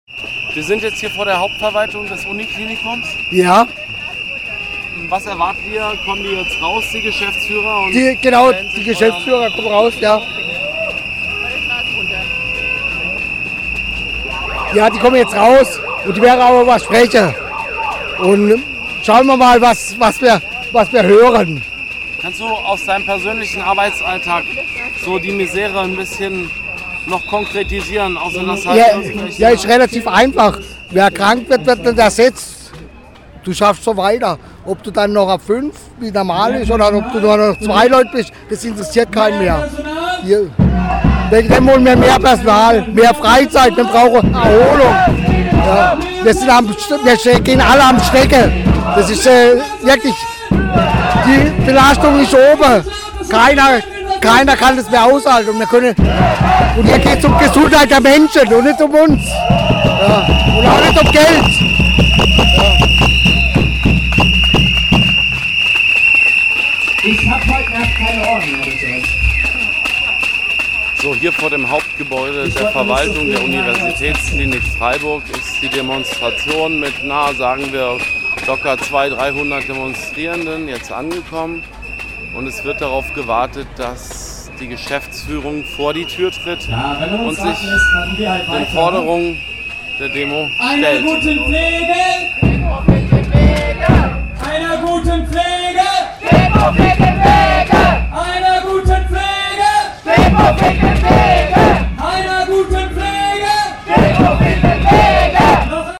In Form einer Demonstration liefen die Gewerkschaftler*innen und die streikende Belegschaft aus der Kilianstraße vor das Hauptgebäude der Veraltung des Uni-Klinikums, in die Breisacher Str. 153.
interviewt Menschen vor dem Verwaltungs-Hauptgebäude: